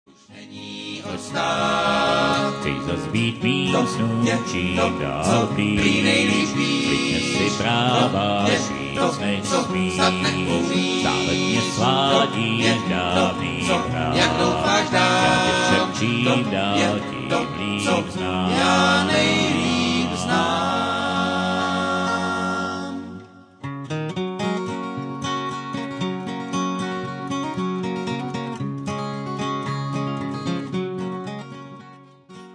banjo
dobro
mandolin